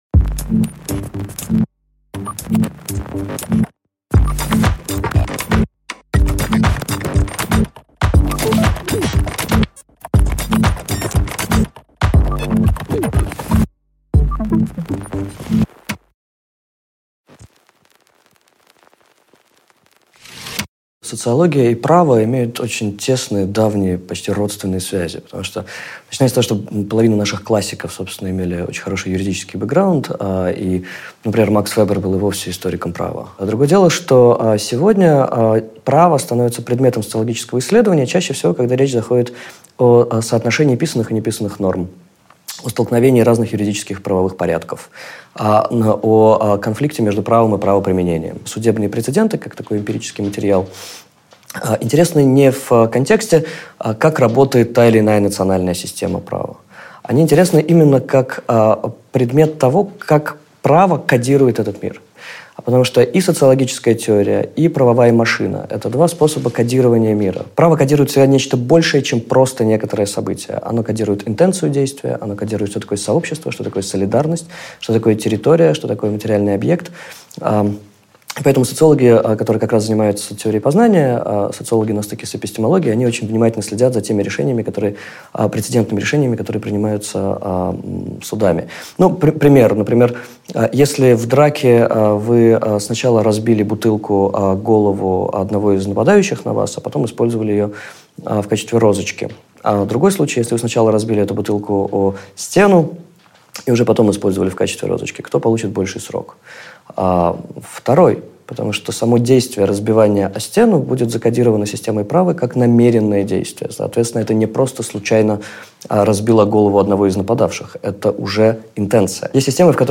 Aудиокнига Как право кодирует мир Автор Виктор Вахштайн.